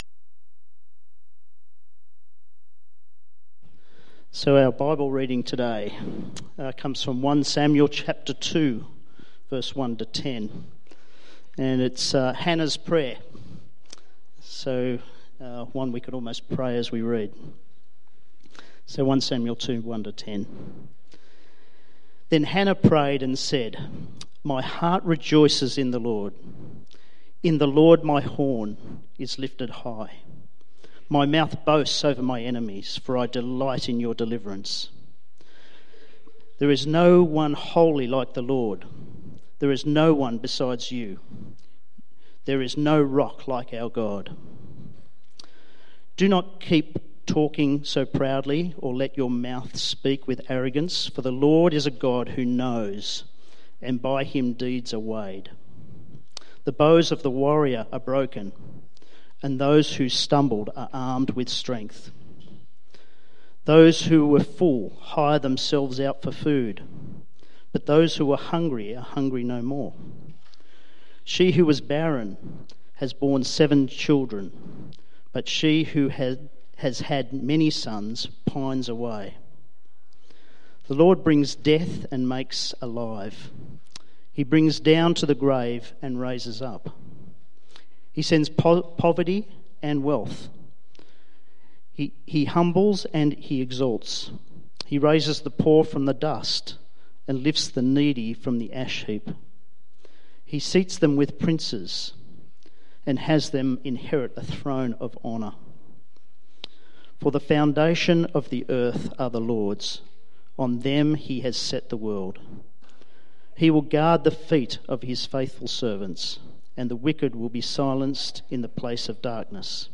Bible Text: 1 Samuel 2:1-10 | Preacher